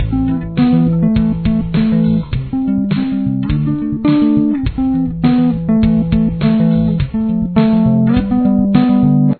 Bass
Finger-pick it throughout.